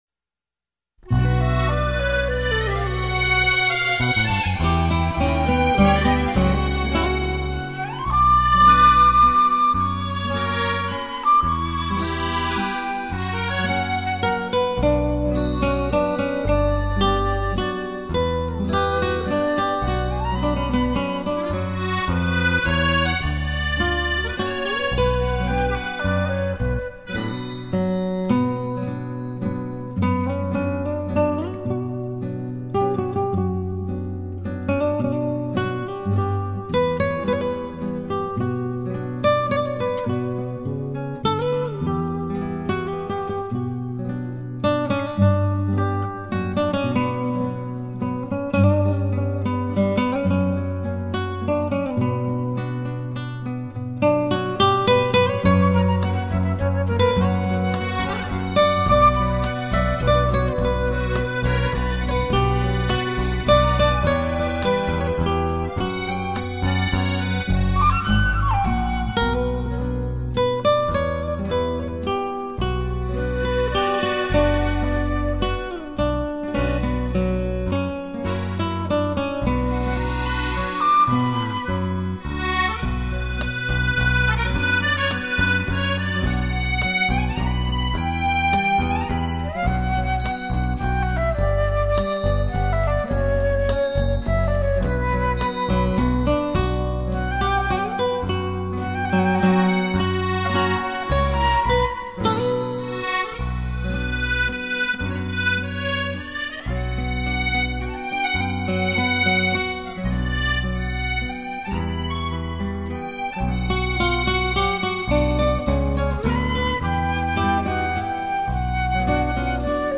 这些经典金曲给你带来无限思念，段段回忆，上好音色，动听旋律，节奏明快，意境浪漫，情怀醉心宝典。